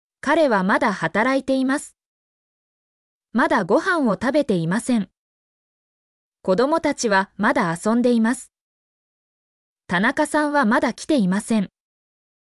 mp3-output-ttsfreedotcom-78_nBROstTg.mp3